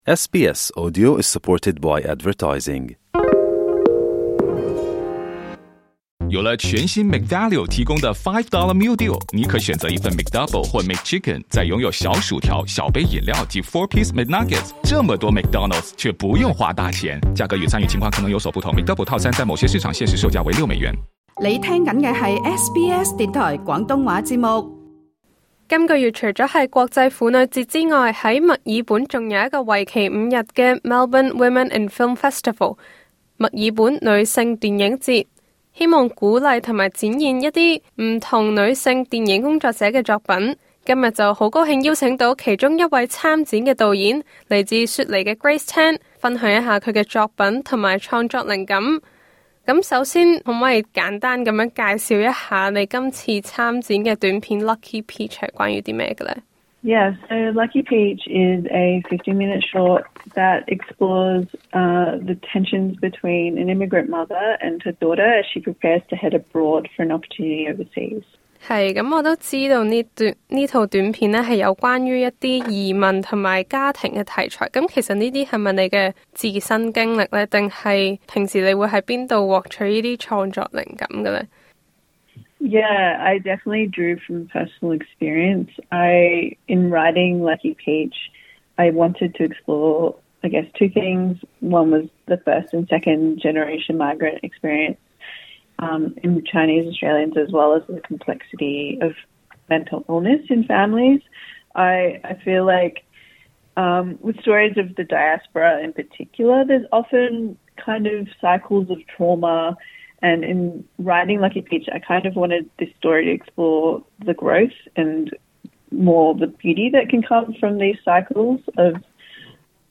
她接受SBS廣東話訪問，分享她爲何喜歡透過電影探討家庭、移民等議題。